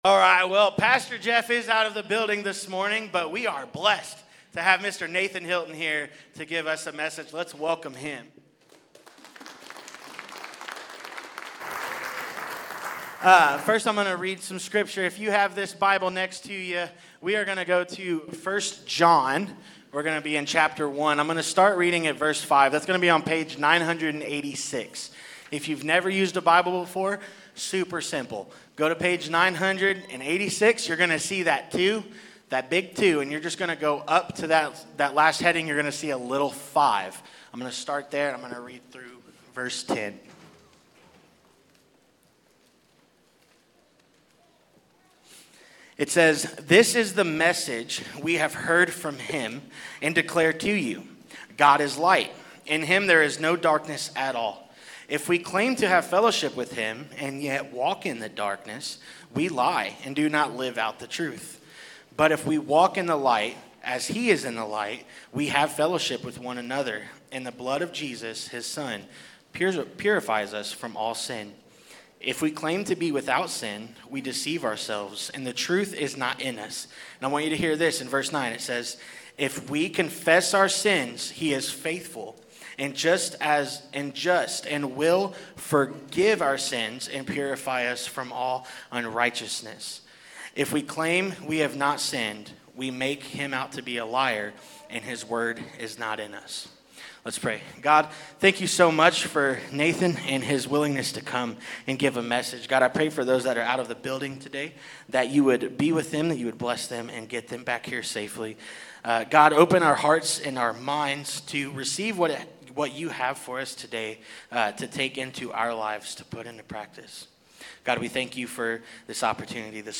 Sermon
A sermon from the series "Guest."